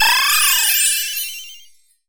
twinkle_glitter_sparkle_spell_01.wav